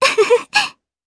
Kirze-Vox_Happy1_jp.wav